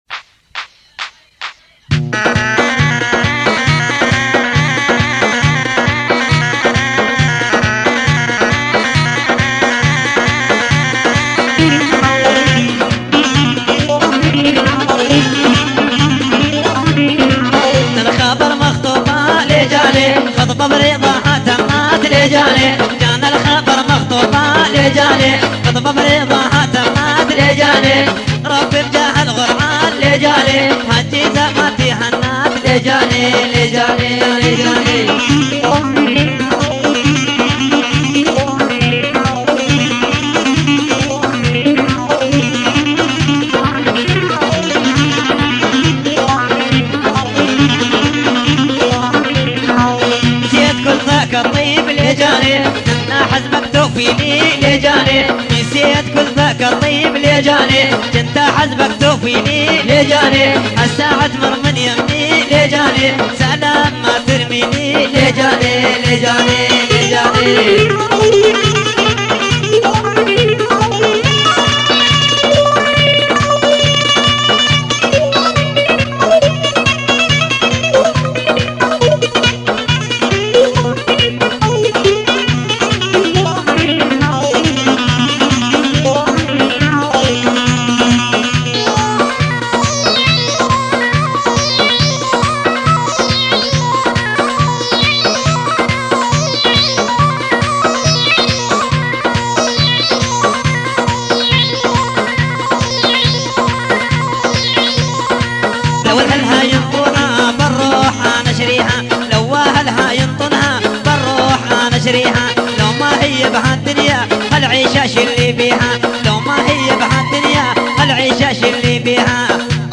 This 90s cassette mega hit from Syria
The song is a perfect blend between east and west.